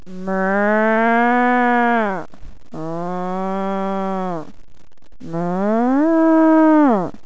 Here are a list of Accents and Impersonations I can do...
Cow Sound